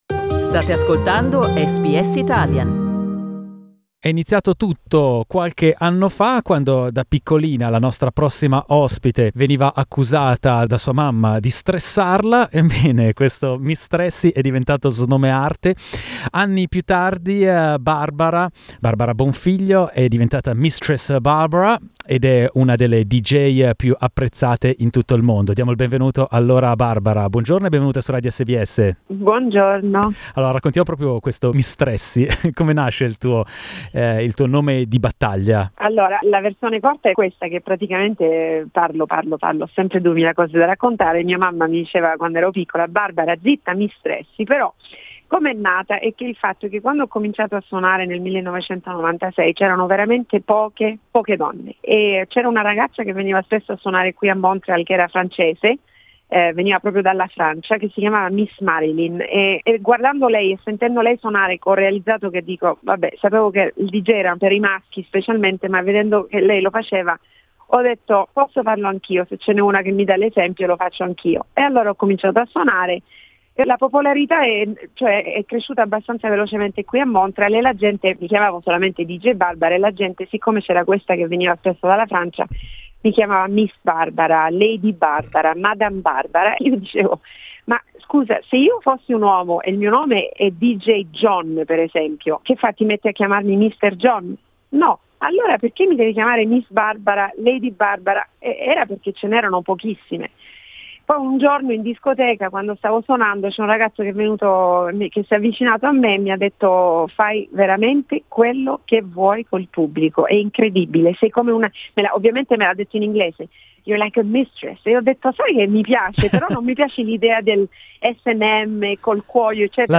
With a successful career that spans over the last 20 years, Misstress Barbara is now in Australia: listen to her story as told to SBS Italian.